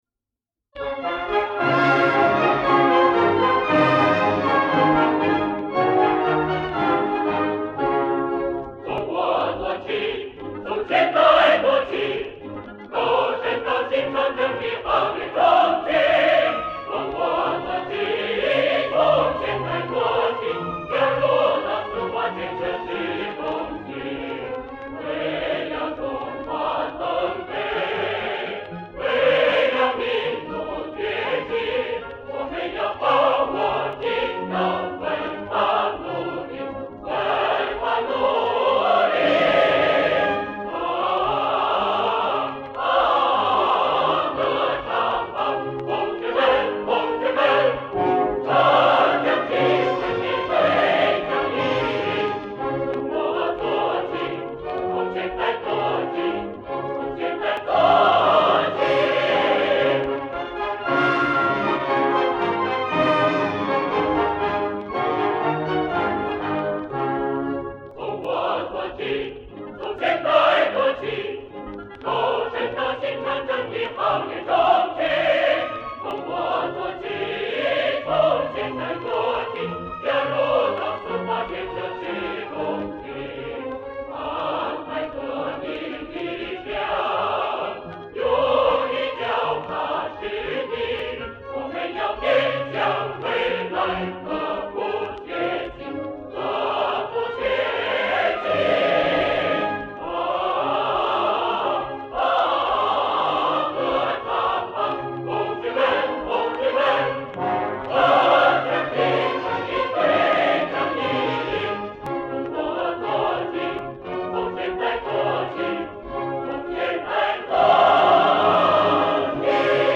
管弦乐队伴奏